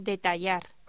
Locución: Detallar
voz